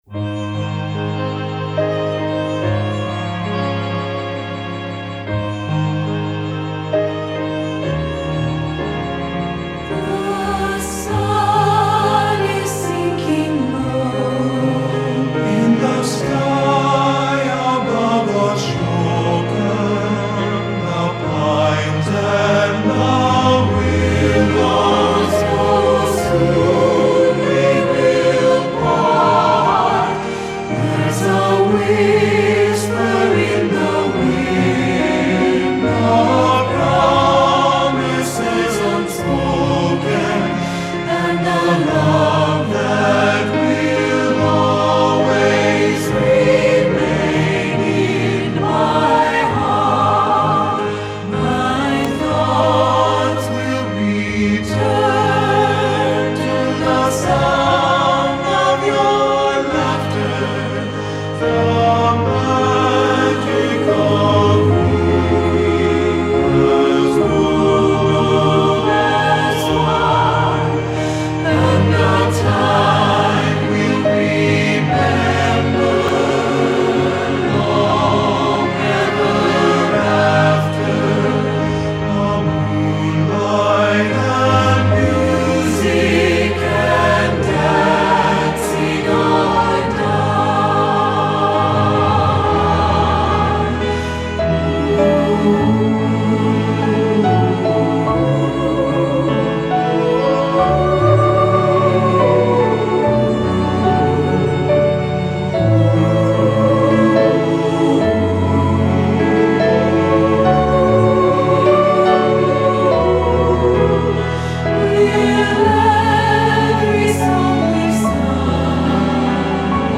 choral
SAB (SATB recording)